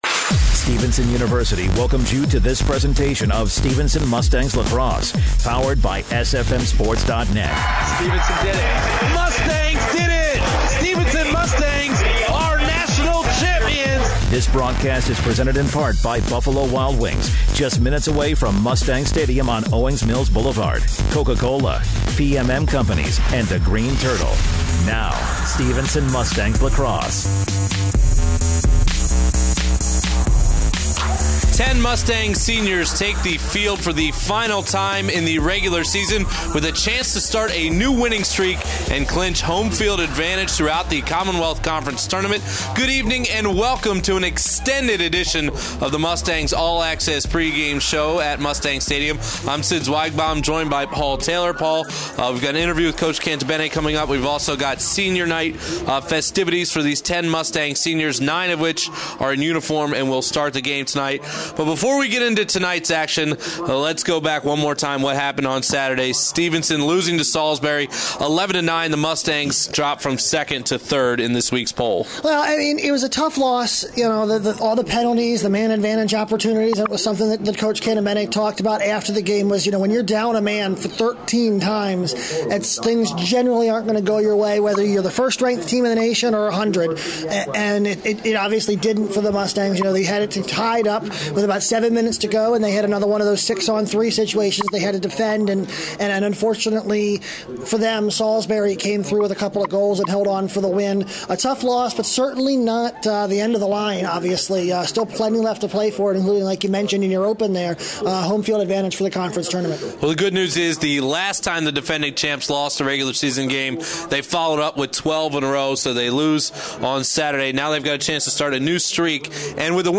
The Mustang host the Messiah Falcons in the final home regular season game of the year during Senior Night.